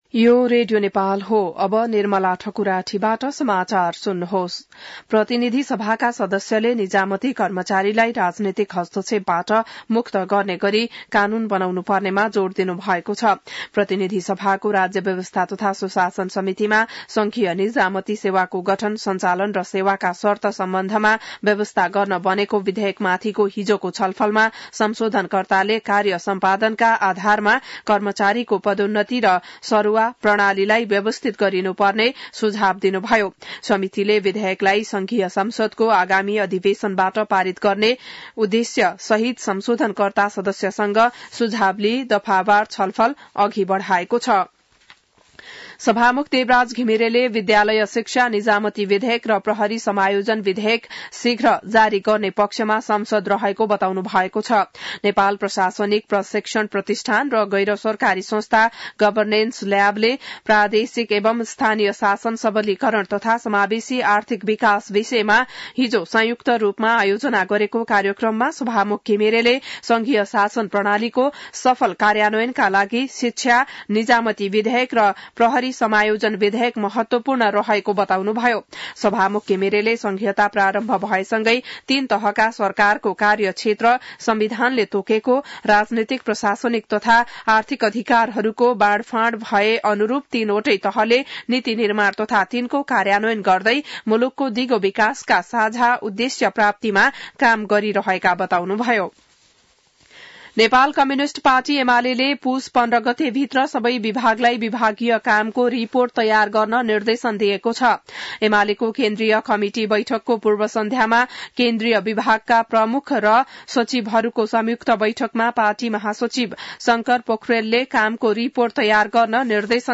An online outlet of Nepal's national radio broadcaster
बिहान १० बजेको नेपाली समाचार : ६ पुष , २०८१